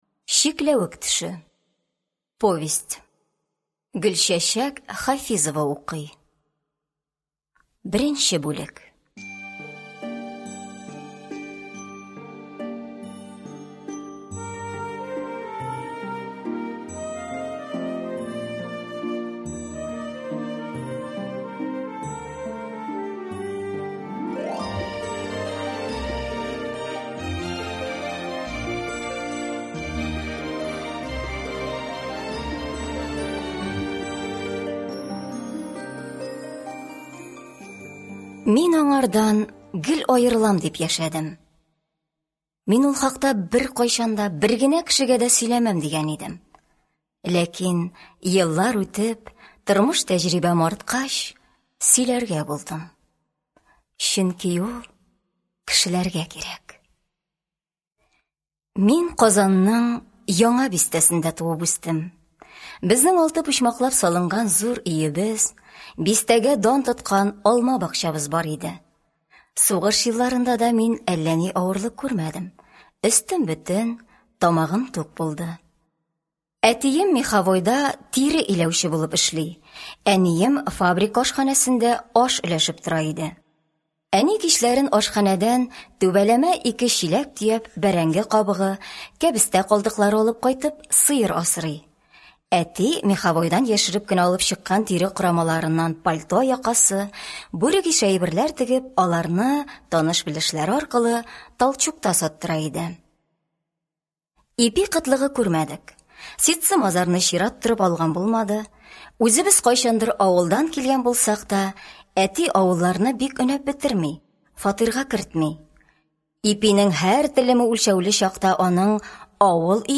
Аудиокнига Чикләвек төше | Библиотека аудиокниг